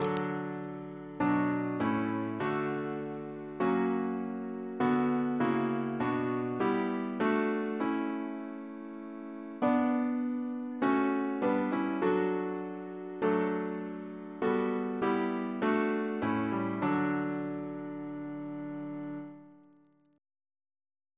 Double chant in D Composer: James Turle (1802-1882), Organist of Westminster Abbey Reference psalters: ACB: 188; ACP: 166; OCB: 93; RSCM: 142